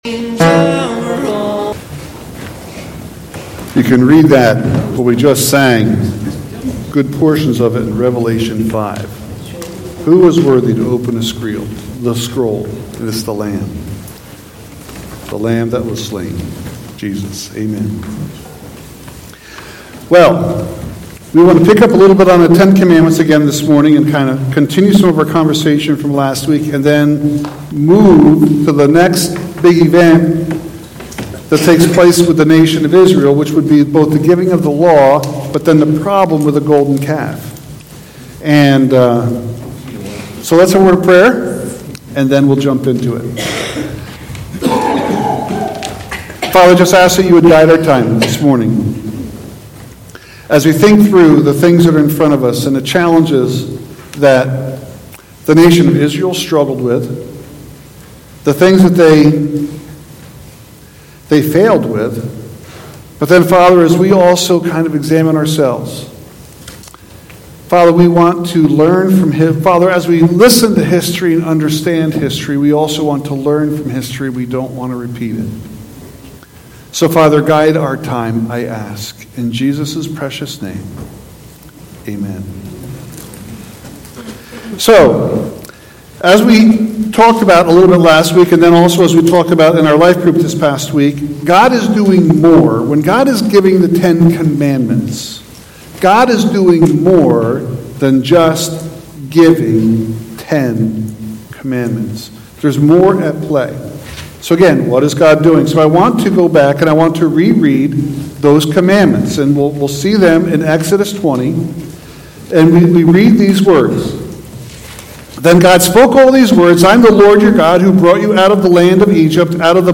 1 Sunday Service 1:02:41